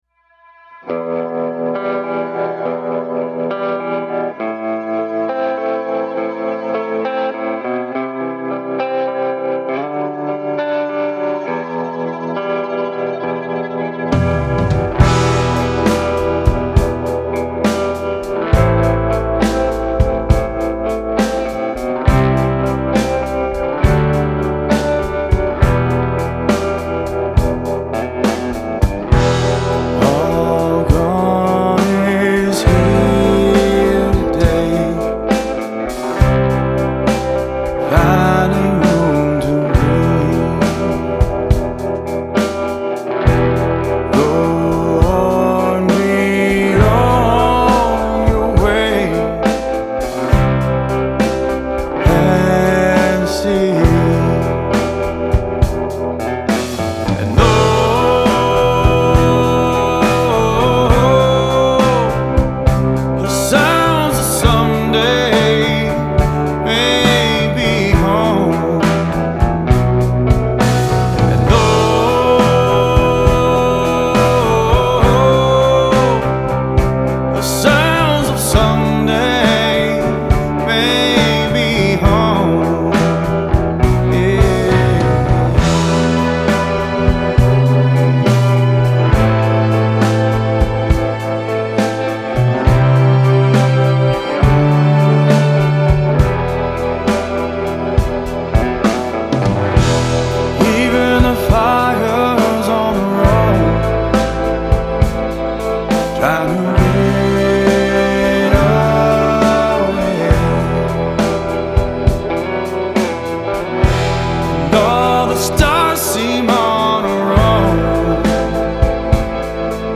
آهنگ راک Blues Rock Alternative Rock